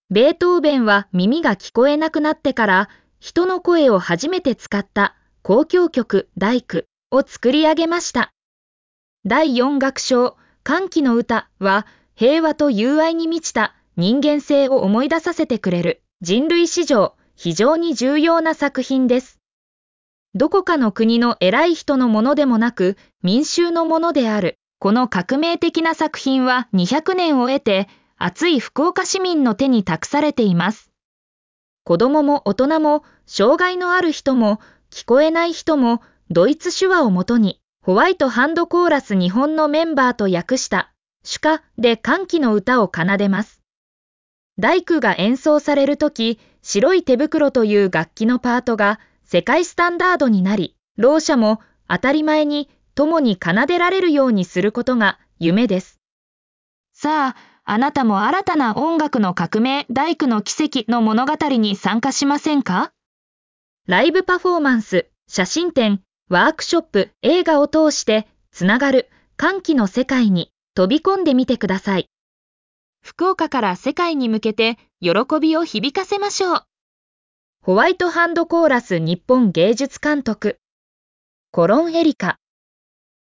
音声読み上げ